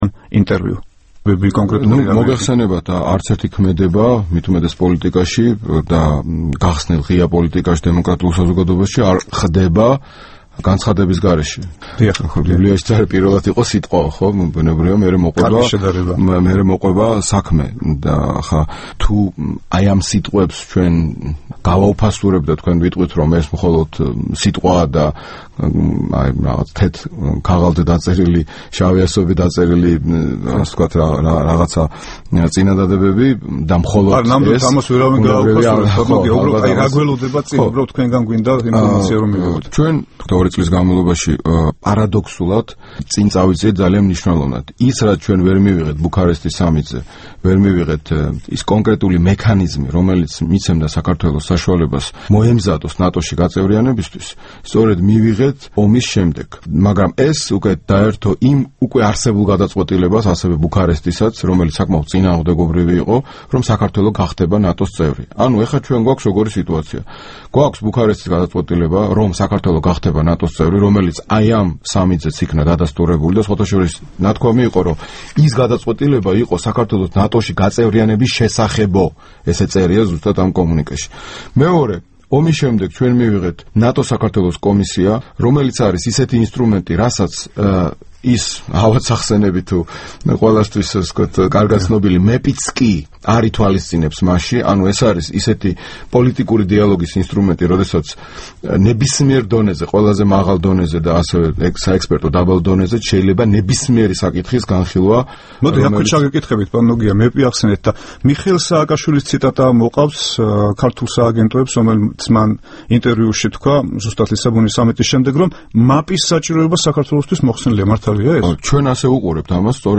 ინტერვიუ გიორგი ბარამიძესთან